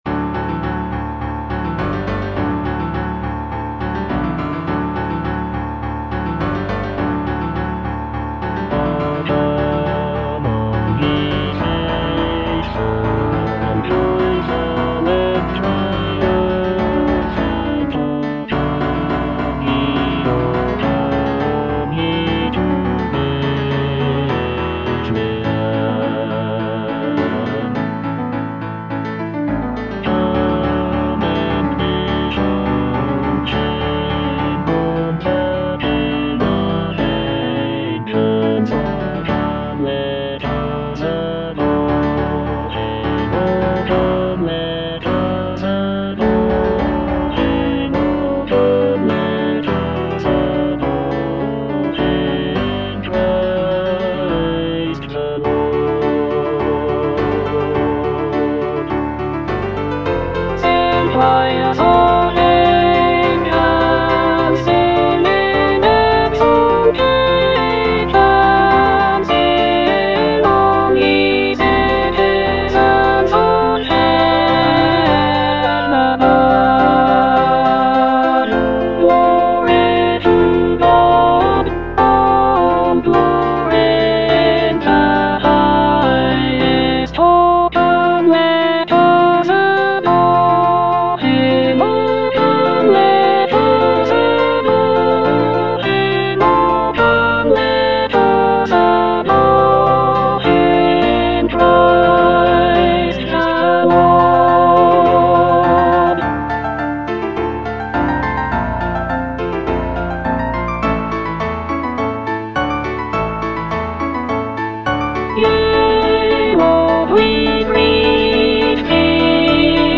Alto I (Emphasised voice and other voices)